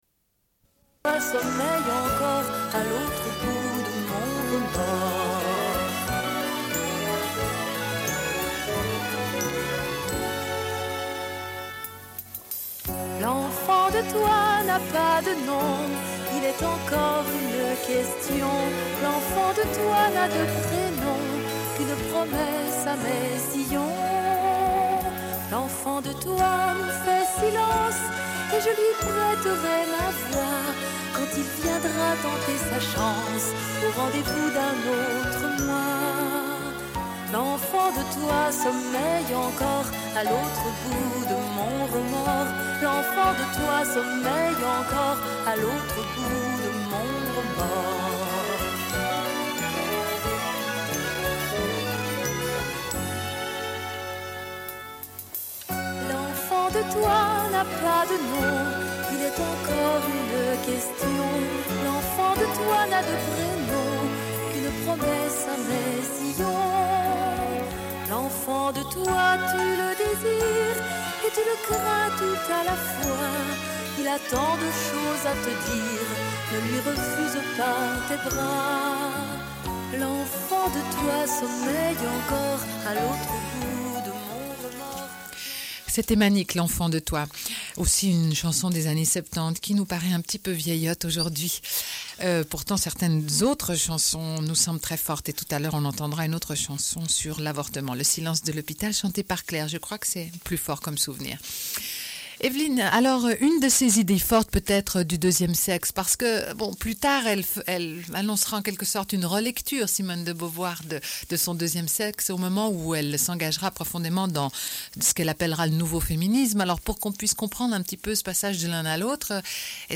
Une cassette audio, face A00:31:31